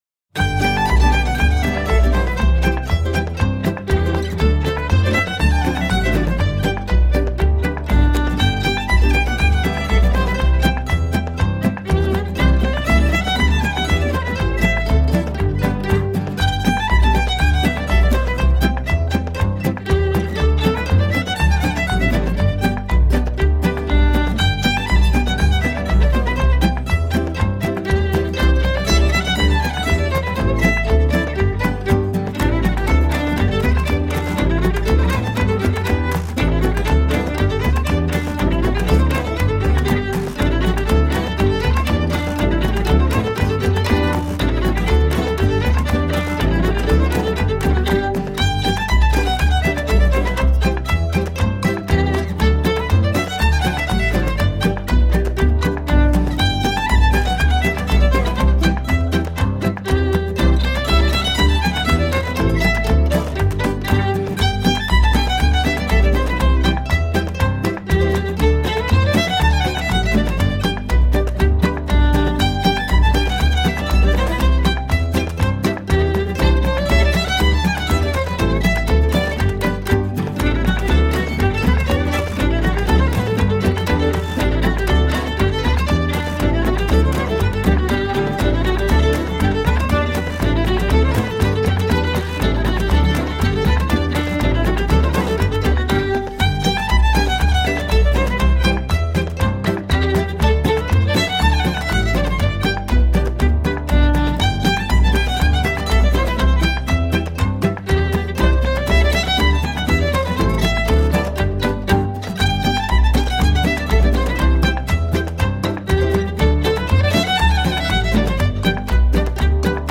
Accordéon / Accordion
Guitar - Banjo - Spoons - Foot tapping
Bodhran - Washboard - Bones